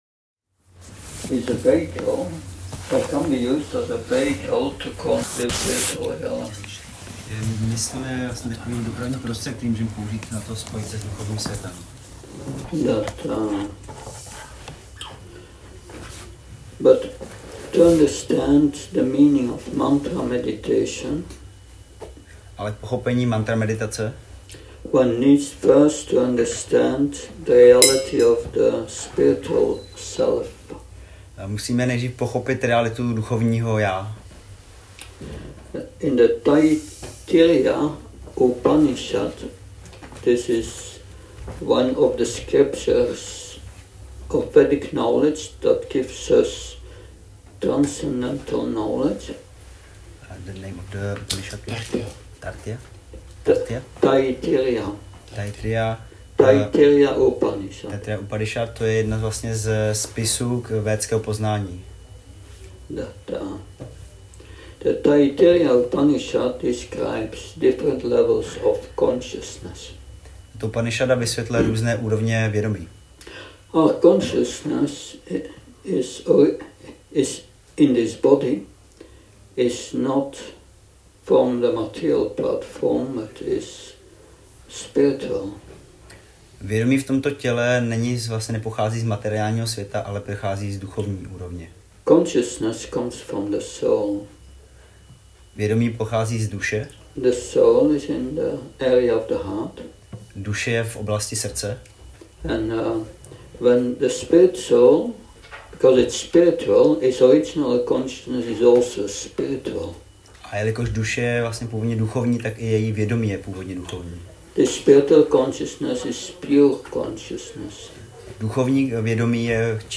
Přednáška – Mantra Party